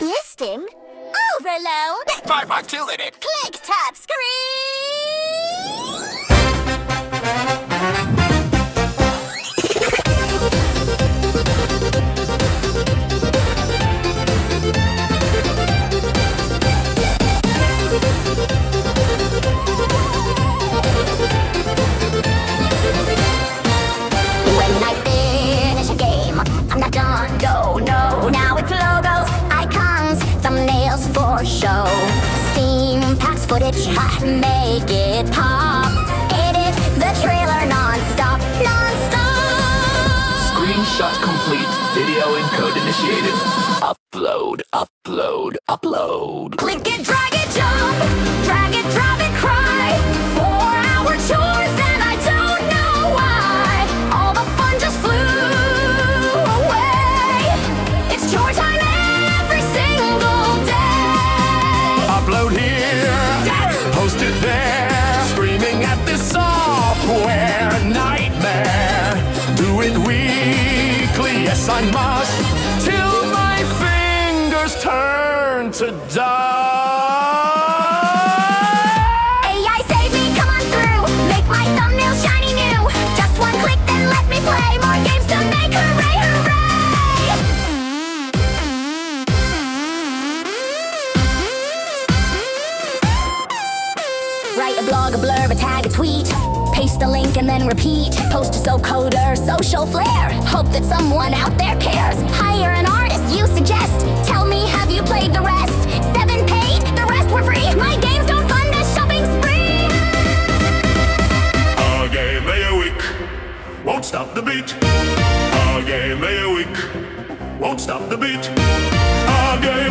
I copy+pasted everything from the "The End Bit" section of the above blog, popped it into the AI, and asked it to turn it into a 90s Europop Dance Track.
Sung by Suno